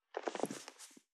439物を置く,バックを置く,荷物を置く,トン,コト,ドサ,ストン,ガチャ,ポン,タン,スッ,ゴト,カチャ,
効果音室内物を置く